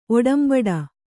♪ oḍambaḍa